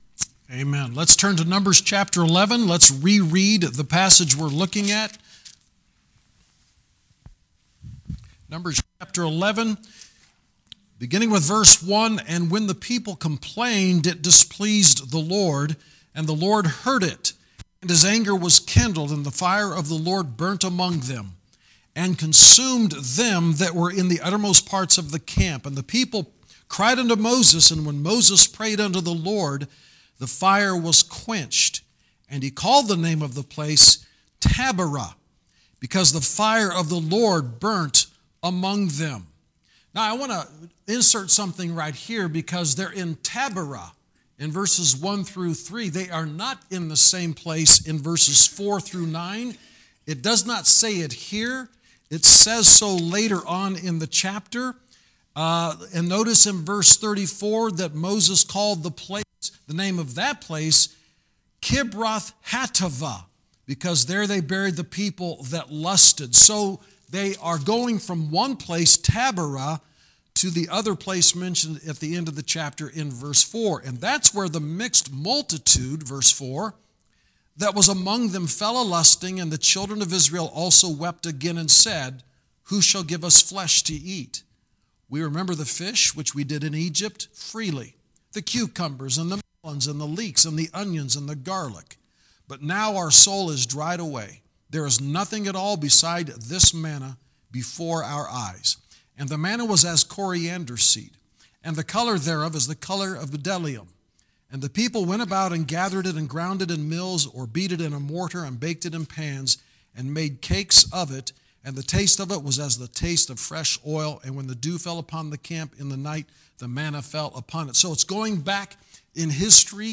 Genre: Sermons.